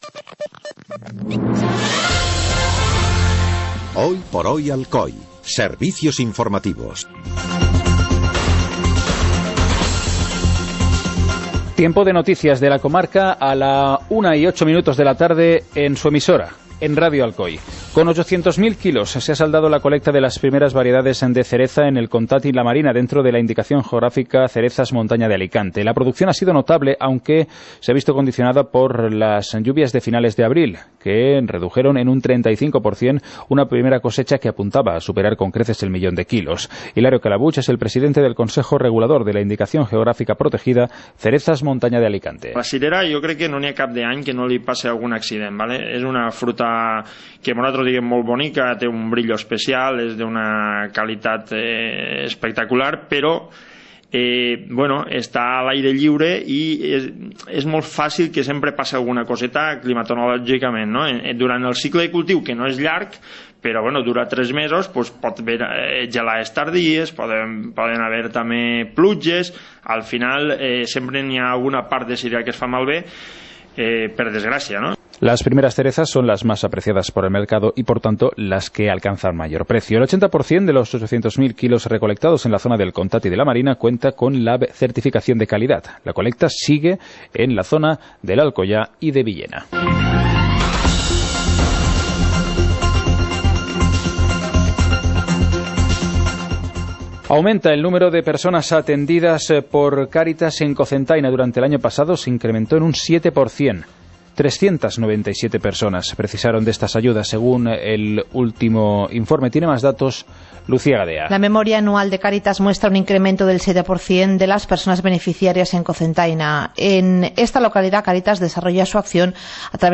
Informativo comarcal - lunes, 19 de junio de 2017